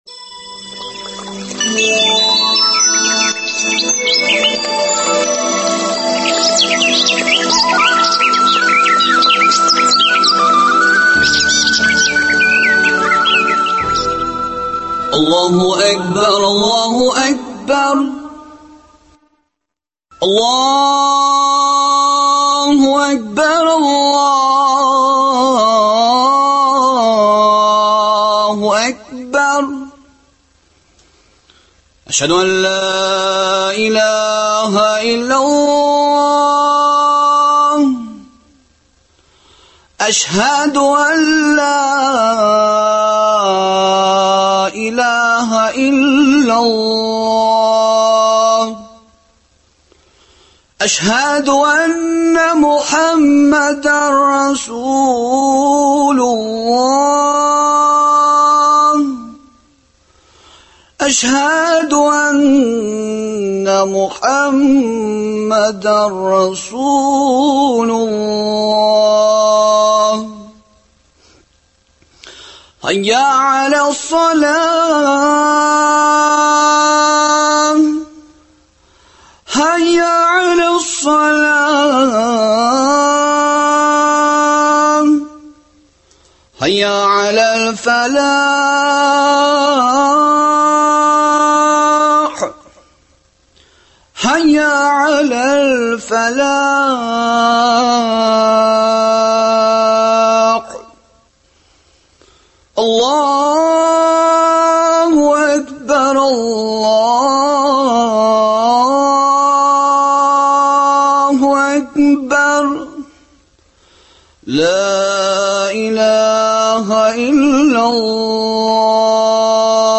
Гаиләдә җылы мөнәсәбәтне саклау мөһим, әмма юллары нинди? Бу сорау турында уйланучыларга әлеге вәгазь ярдәмгә килер.